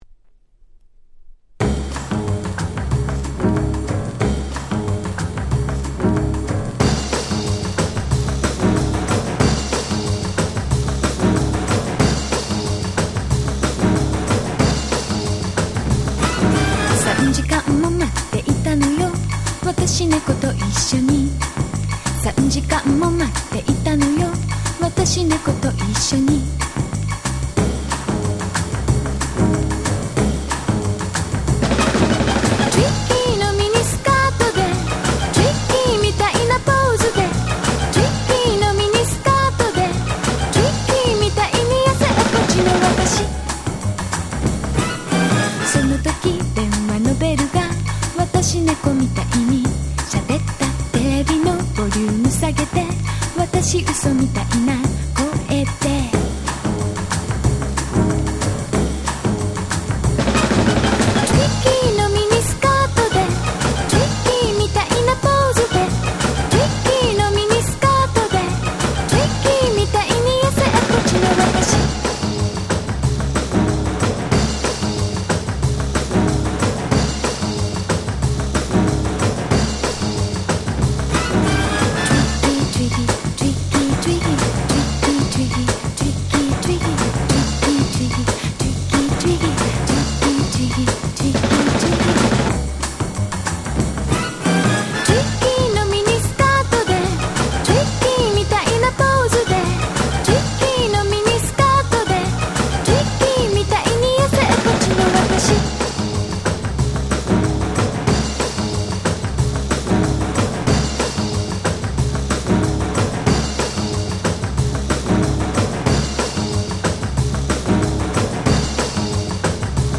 91' Very Nice J-Pop !!